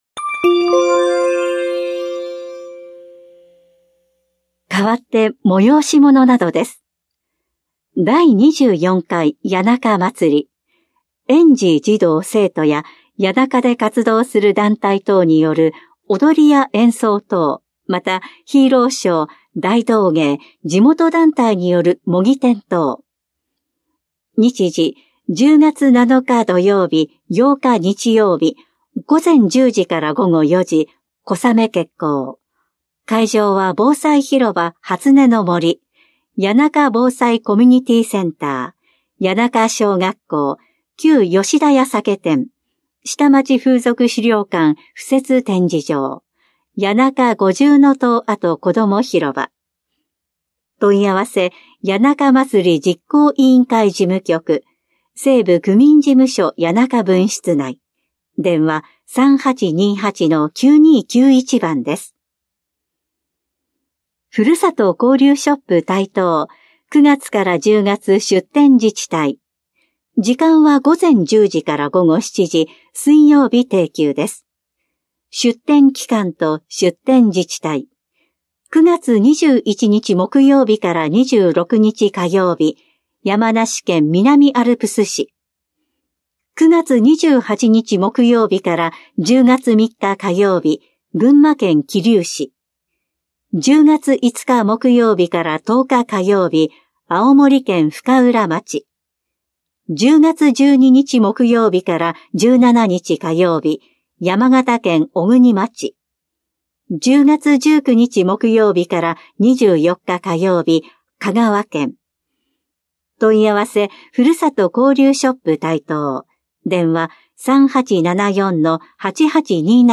広報「たいとう」令和5年9月20日号の音声読み上げデータです。